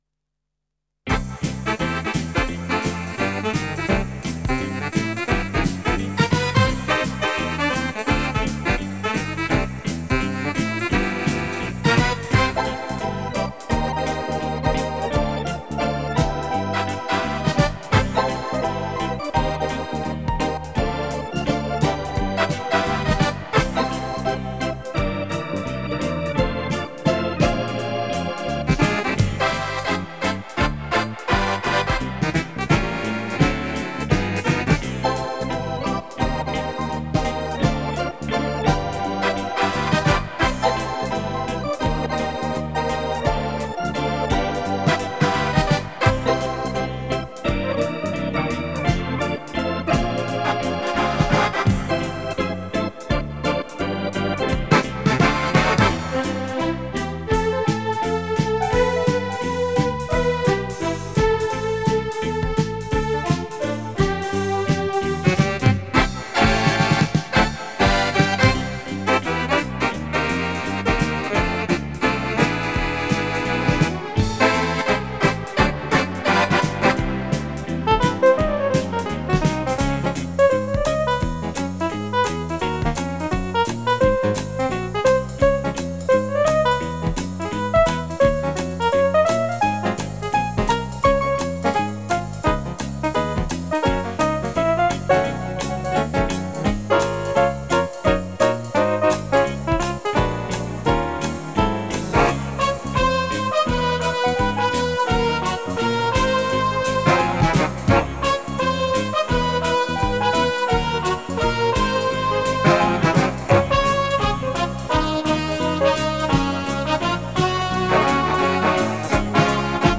Жанр: Instrumental, Tribut